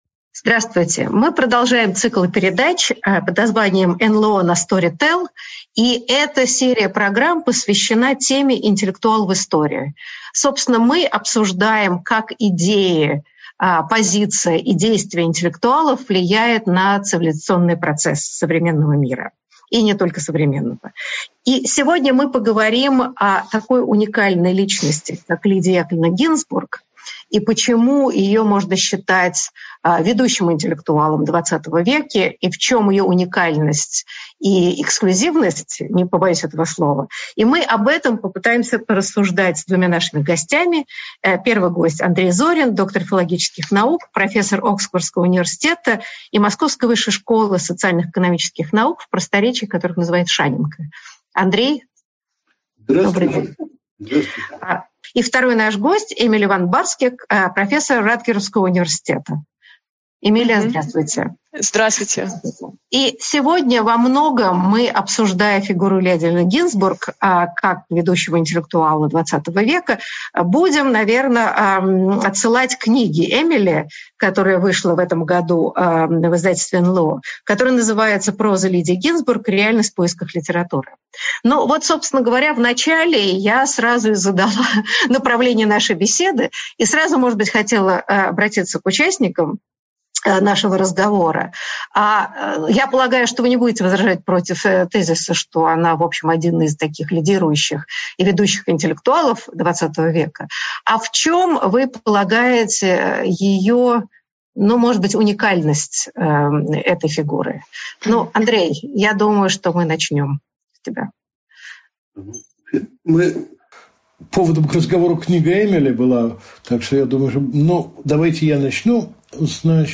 Аудиокнига Интеллектуал в истории: Лидия Гинзбург | Библиотека аудиокниг